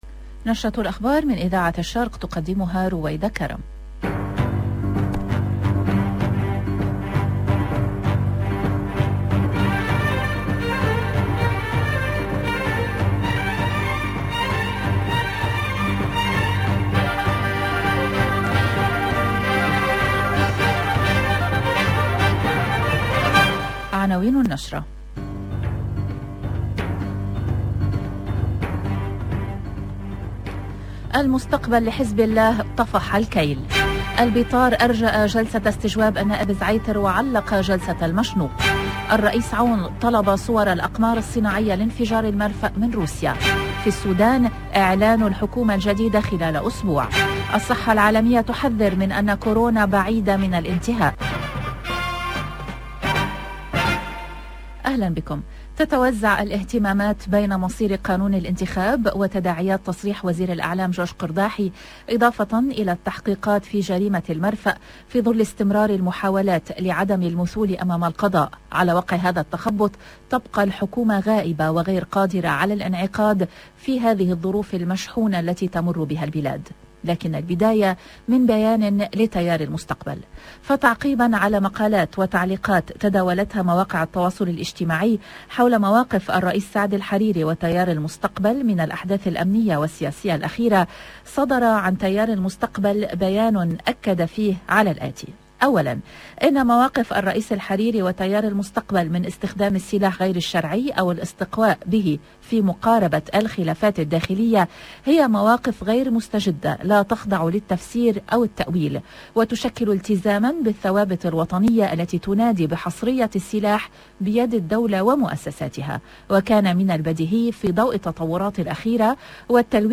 LE JOURNAL DU LIBAN DE 17H00 EN LANGUE ARABE DU 29/10/2021